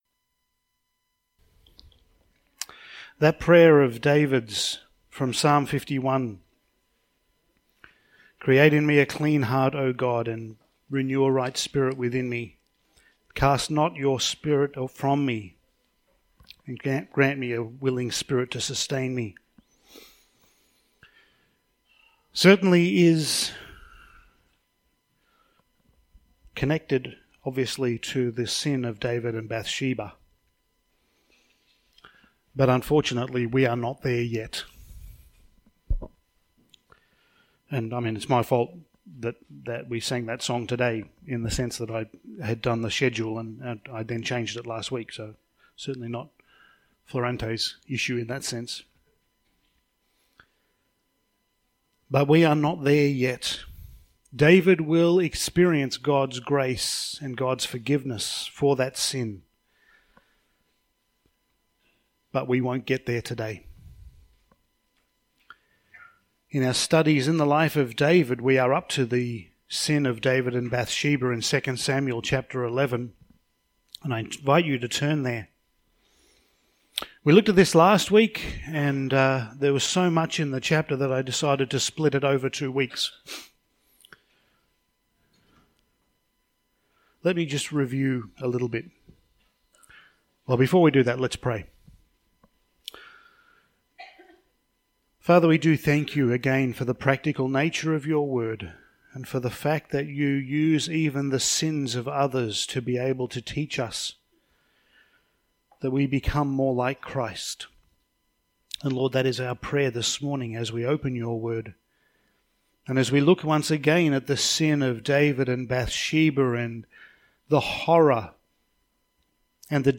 Passage: 2 Samuel 11:1-27 Service Type: Sunday Morning